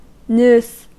Ääntäminen
Synonyymit mind competence Ääntäminen US brittisk engelska: IPA : /naʊs/ Tuntematon aksentti: IPA : /nuːs/ Haettu sana löytyi näillä lähdekielillä: englanti Käännös Konteksti Substantiivit 1.